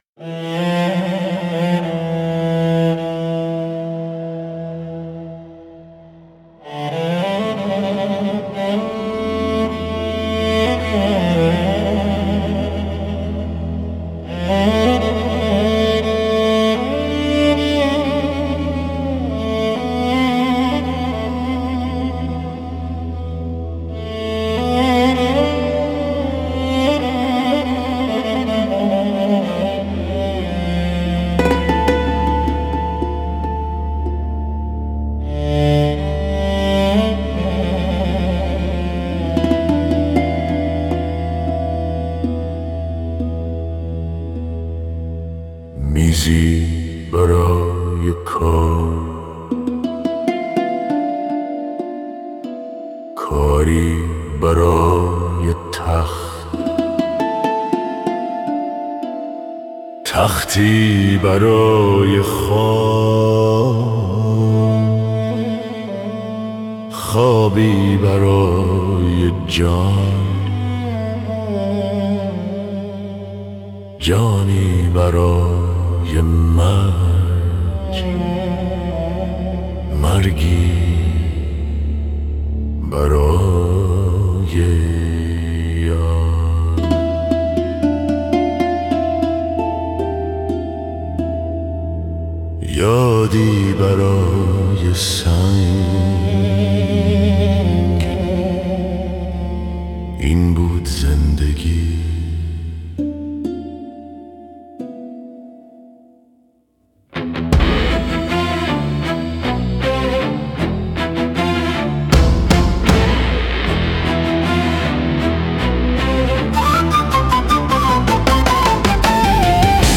Vocals: Male Vocal + Mixed Choir 🎙
Genre: Persian Traditional × Rock × World Fusion ⚡